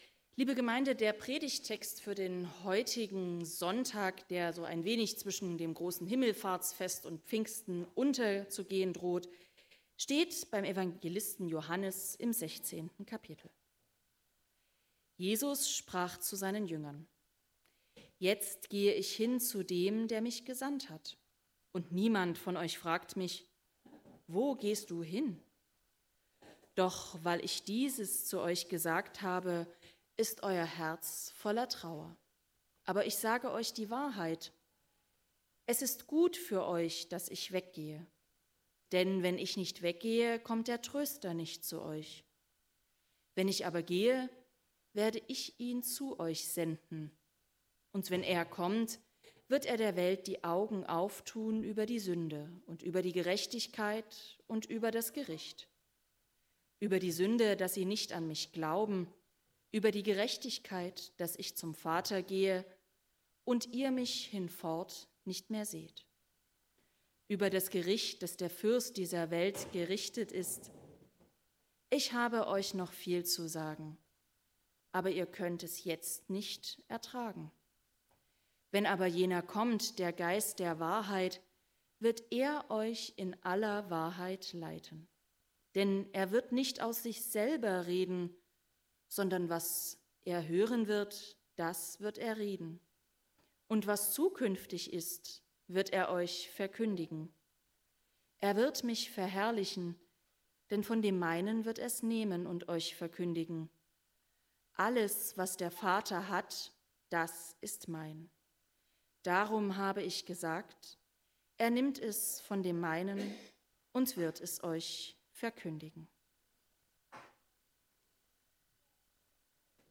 Prediger: